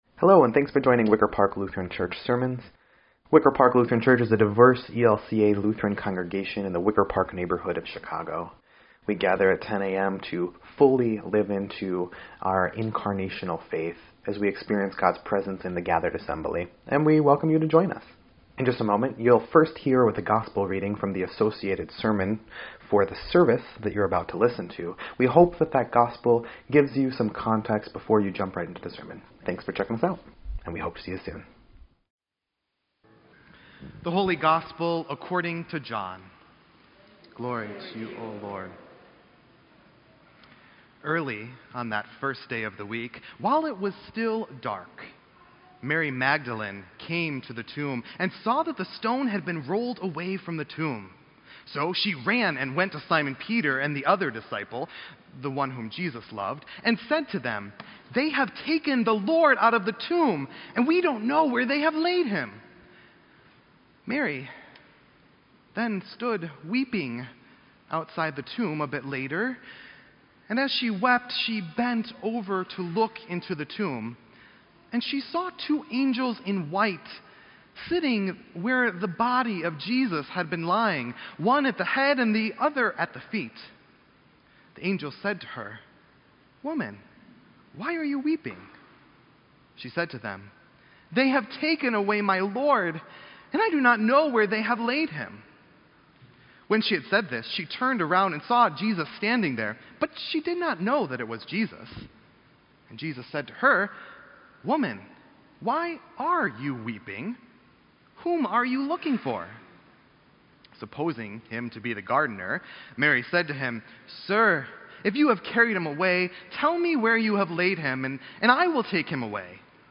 Wicker Park Lutheran Church